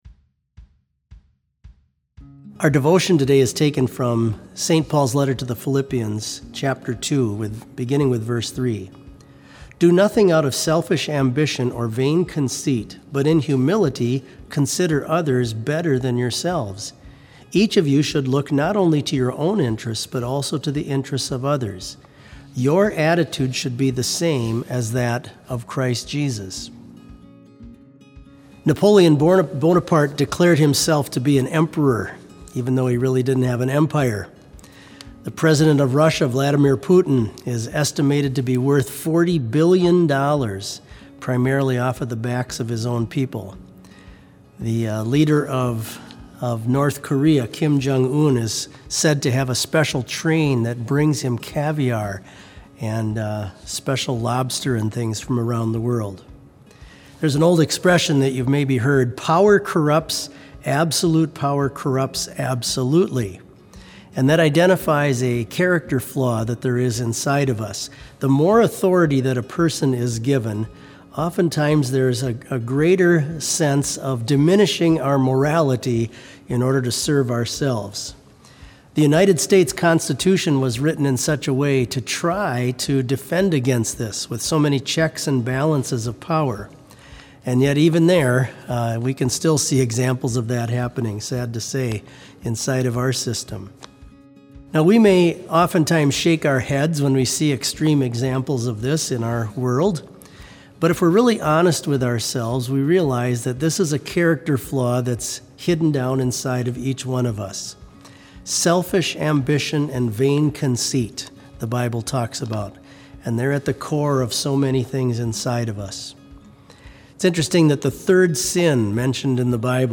Complete service audio for BLC Devotion - April 2, 2020